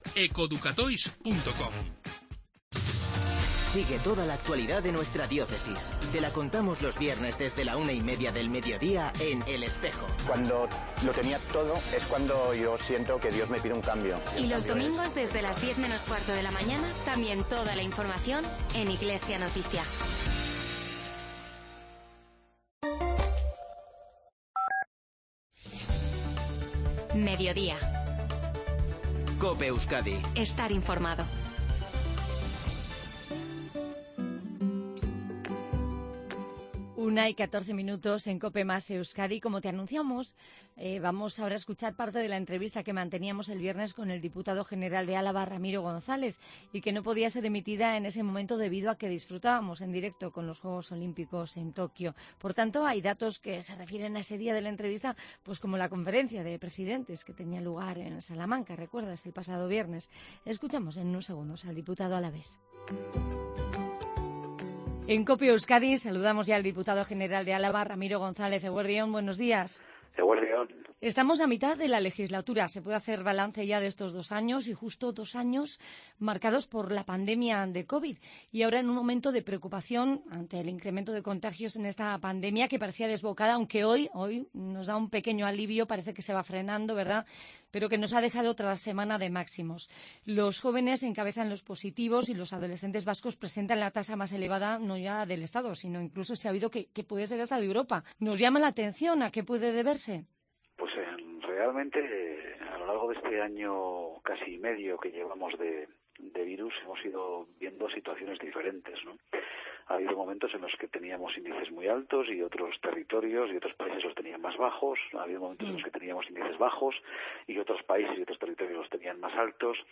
Con ella hemos hablado en COPE Euskadi.